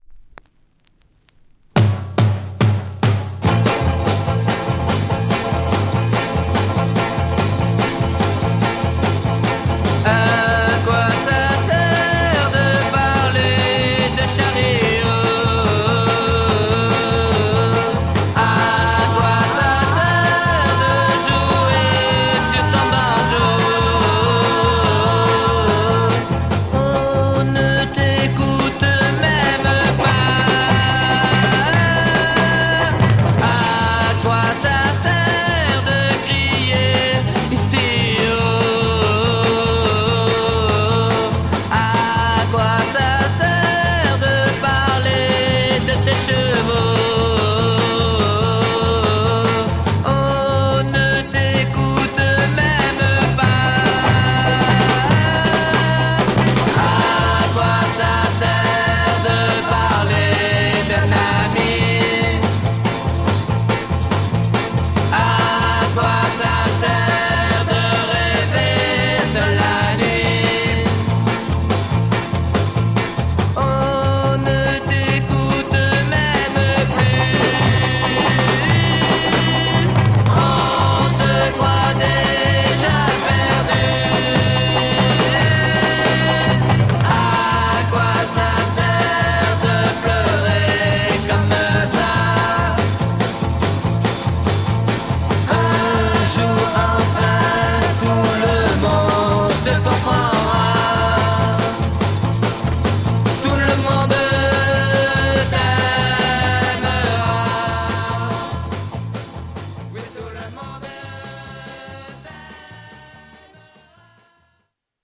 French garage folk punk EP